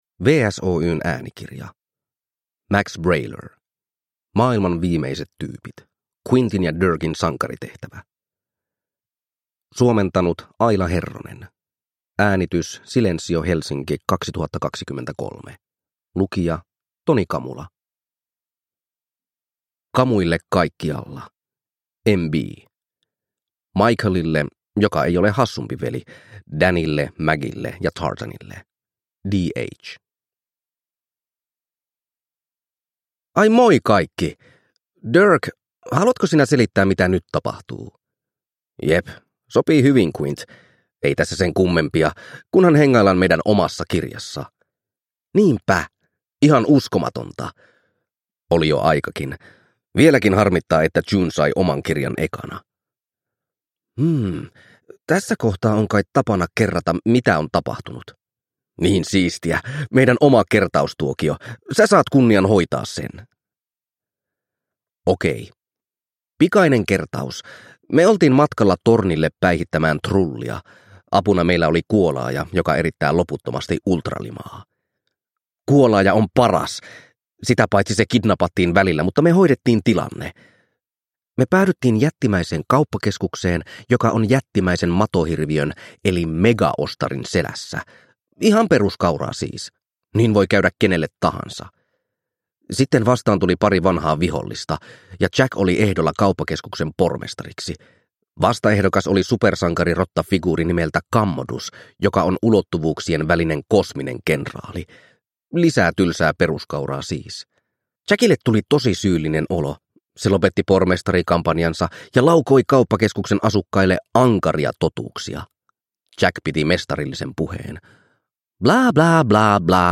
Maailman viimeiset tyypit: Quintin ja Dirkin sankaritehtävä – Ljudbok – Laddas ner